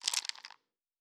Dice Shake 8.wav